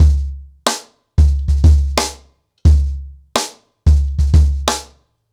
CornerBoy-90BPM.9.wav